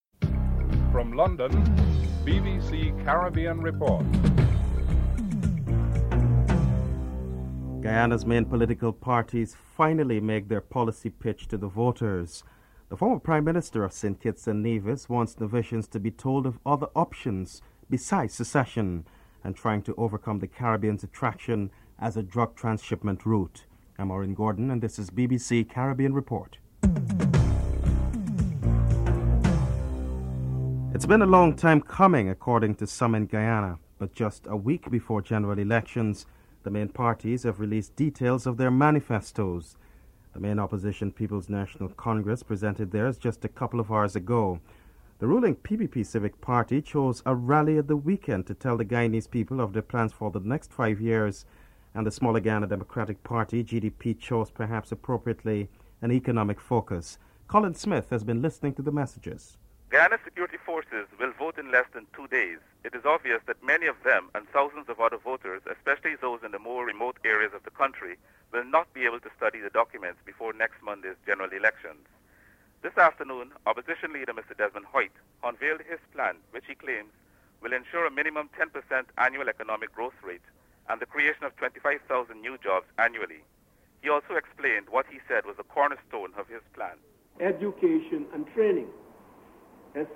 Former Prime Minister Kennedy Simmonds is interviewed